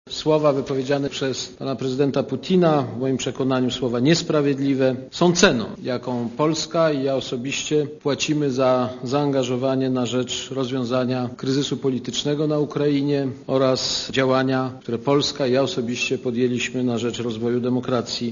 Posłuchaj prezydenta Kwaśniewskiego
Oceniam, iż słowa wypowiedziane dzisiaj przez prezydenta Putina, w moim przekonaniu są niesprawiedliwe - oświadczył Kwaśniewski na konferencji prasowej w Pałacu Prezydenckim.